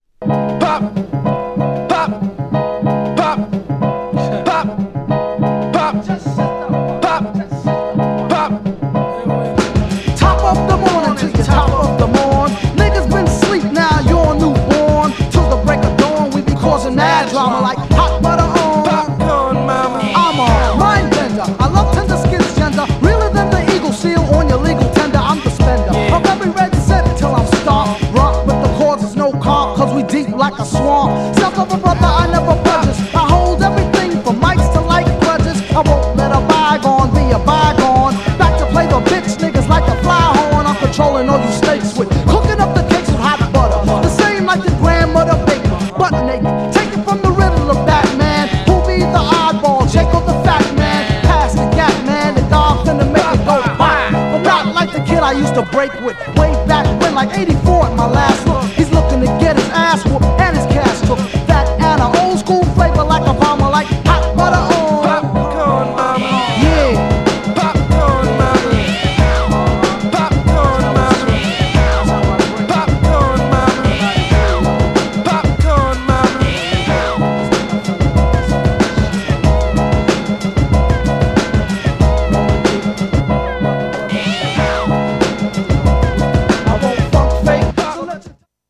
GENRE Hip Hop
BPM 101〜105BPM
DOPEなビート # GROOVYなHIPHOP
# スモーキーなトラック # ベースうねってますけど